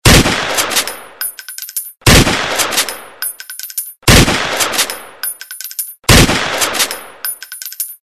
Звуки перезарядки оружия
Выстрел, гильза падает, дробовик перезаряжается